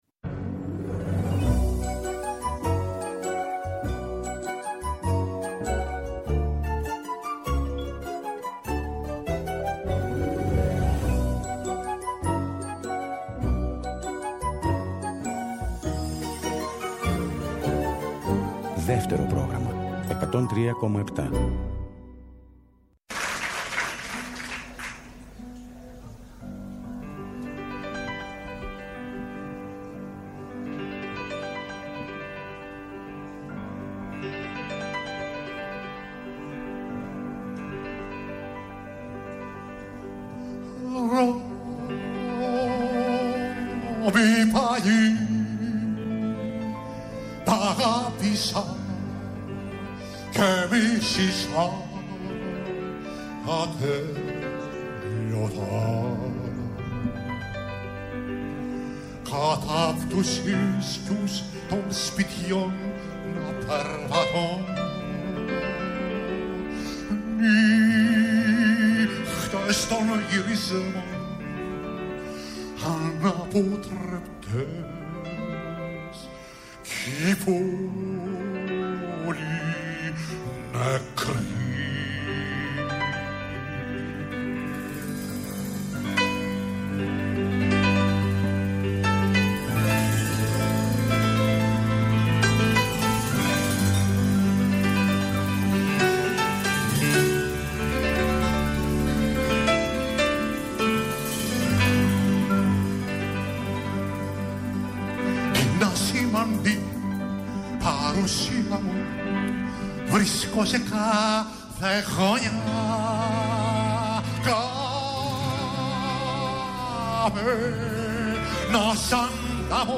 θα μεταδώσει ηχητικά ντοκουμέντα από εκείνη τη βραδιά